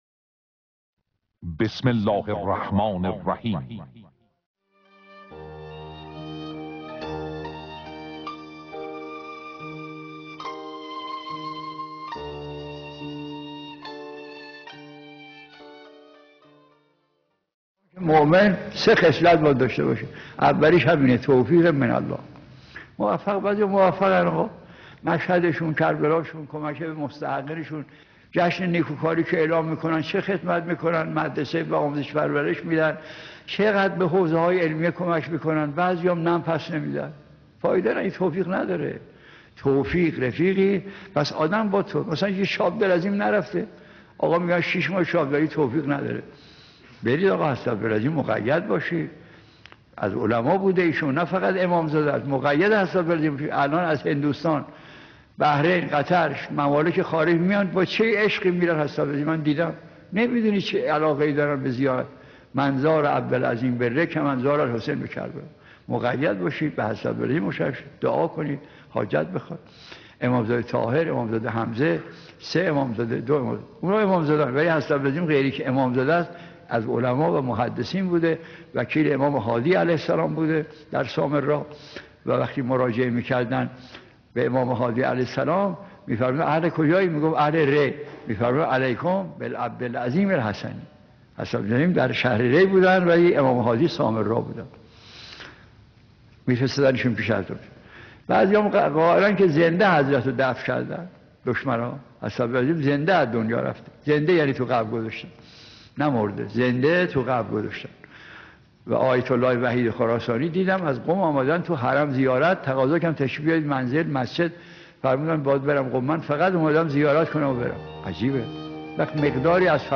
حضرت عبدالعظیم حسنی را در کلام آیت الله مجتهدی تهرانی